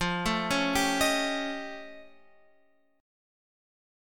F Augmented 9th